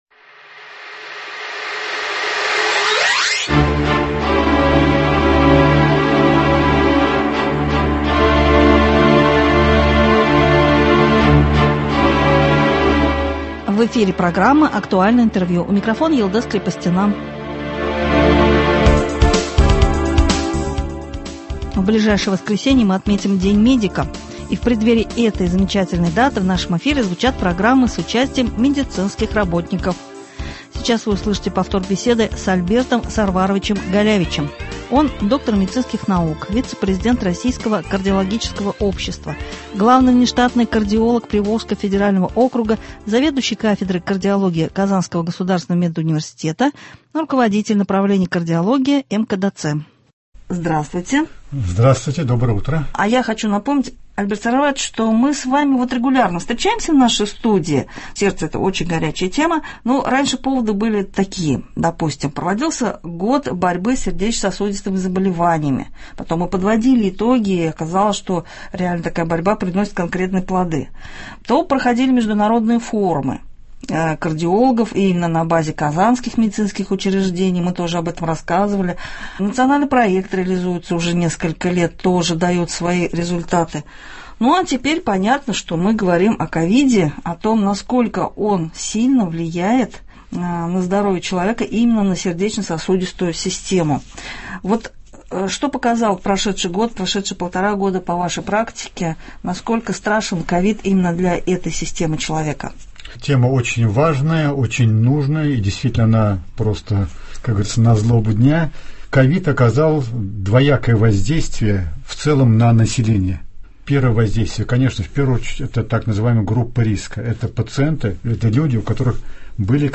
Актуальное интервью (16.06.21)